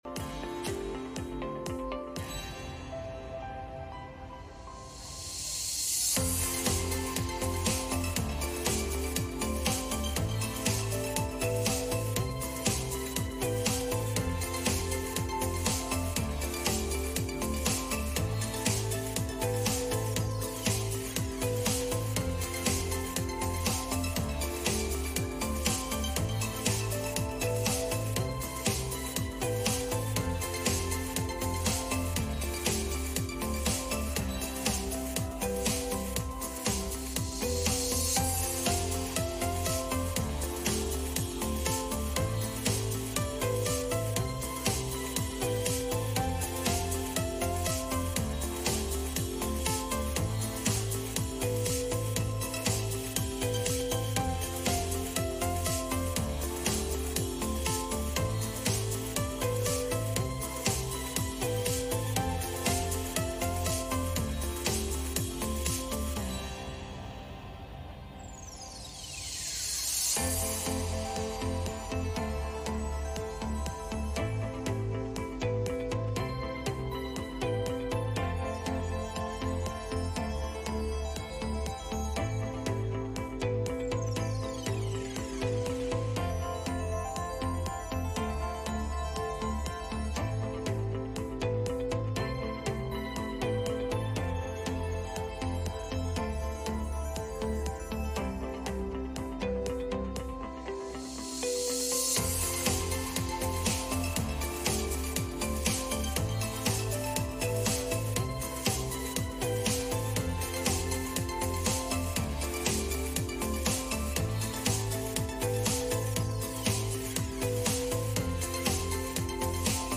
John 14:13 Service Type: Sunday Morning « How Should We Approach Thanksgiving?